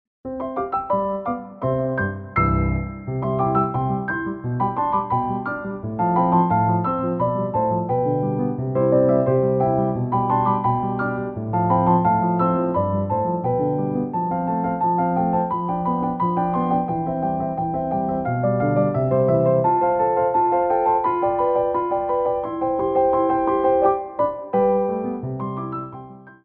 Petit Allegro 1
2/4 (16x8)